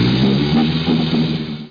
00879_Sound_ship.mp3